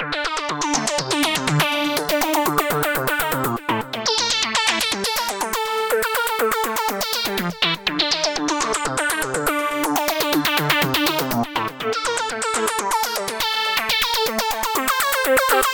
122 BPM Beat Loops Download